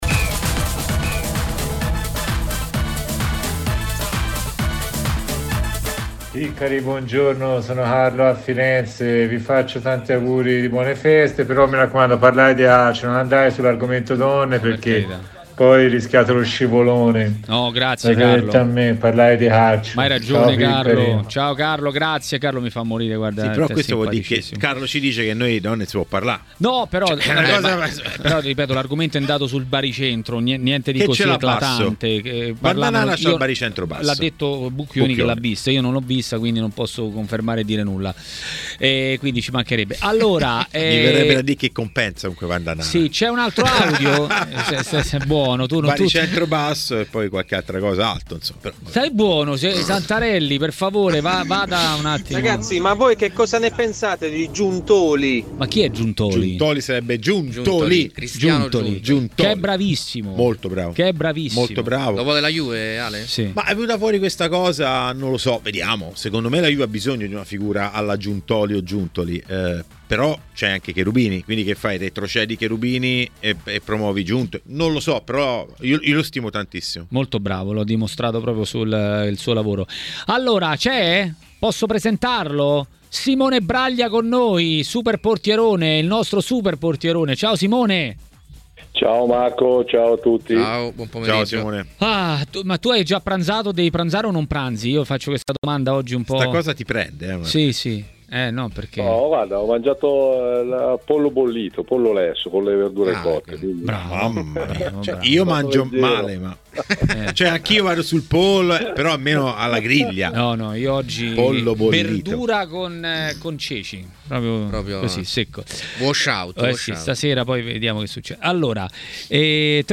in studio